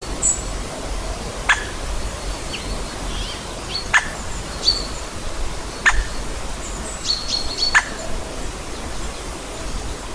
White-backed Woodpecker, Dendrocopos leucotos
Administratīvā teritorijaLīvānu novads
StatusVoice, calls heard